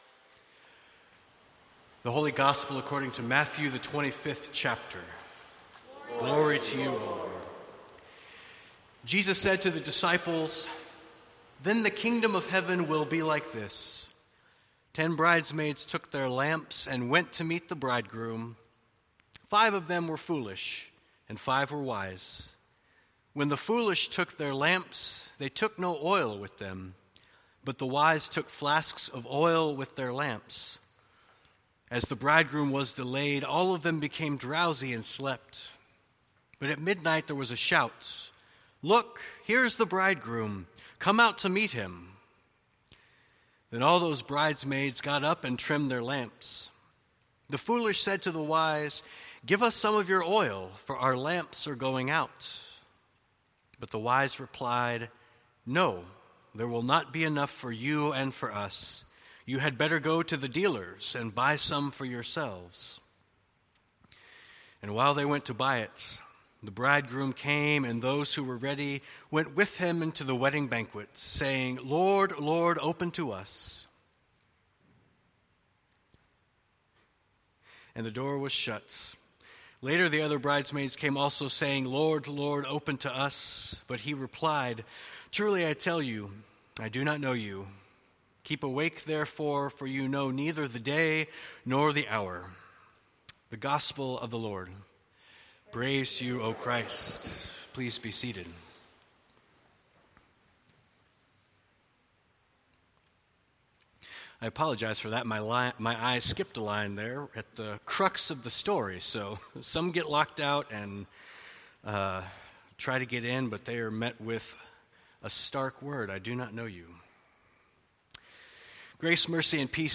Sermons | First Evangelical Lutheran Church | Page 7